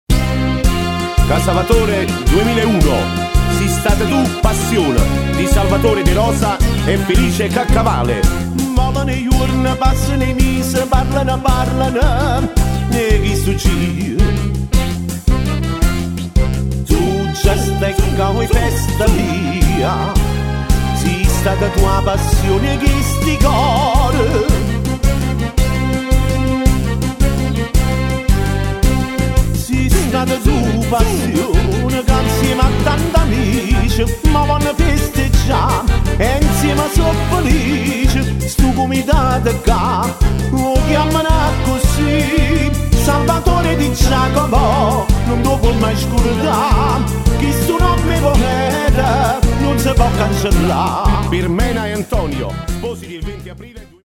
Paranza Casavatorese: Nuova Gioventù